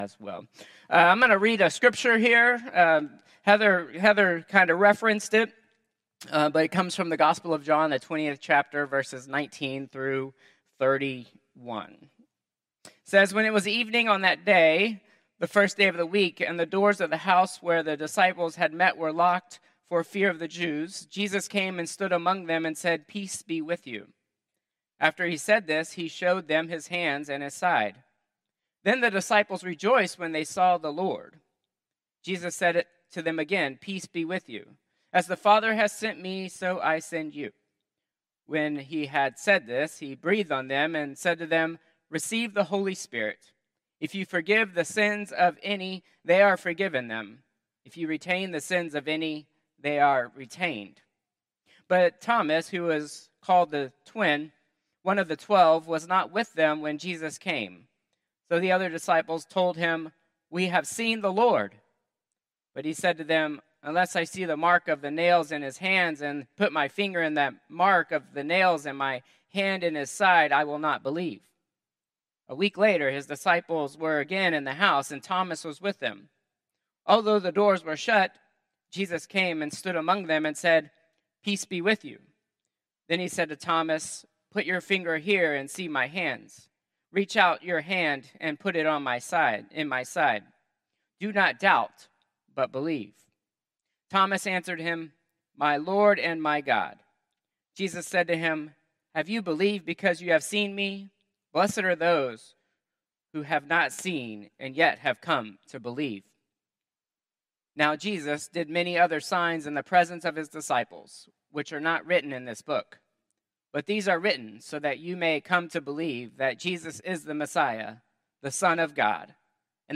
Contemporary Service 4/27/2025